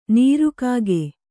♪ nīru kāge